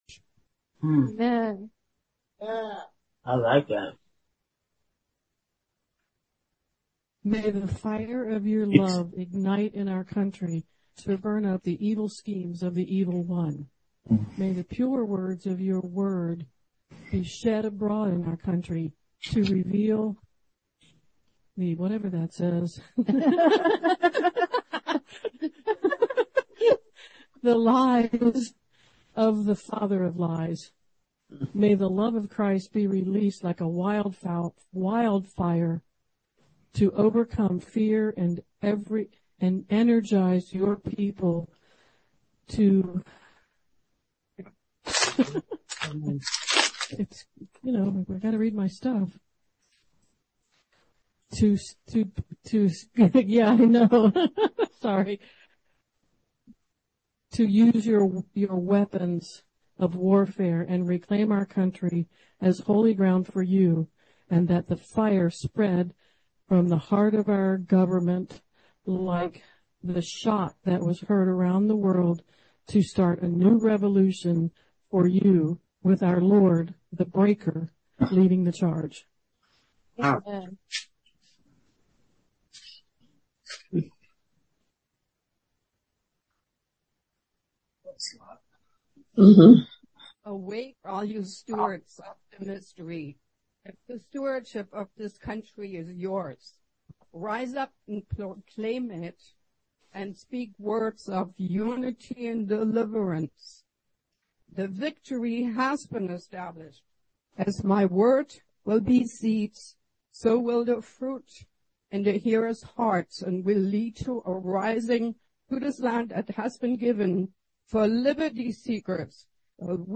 Armor of God Seminar 2024 Part 3 cat-aog